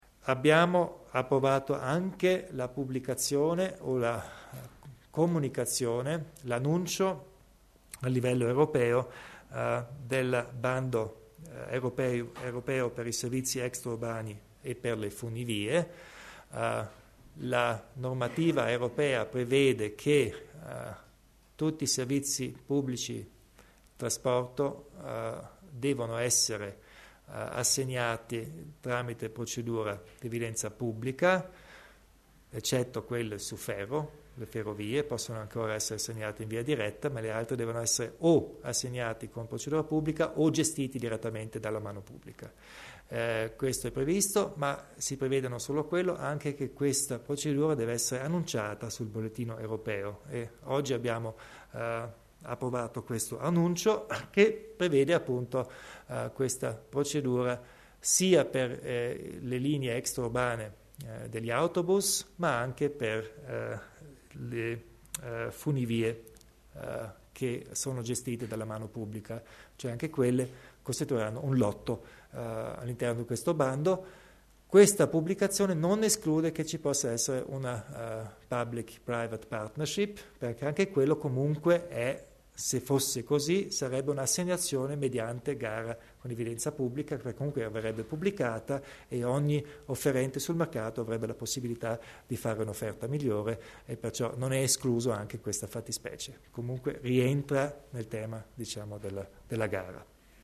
Il Presidente Kompatscher elenca le novità in materia di trasporto pubblico.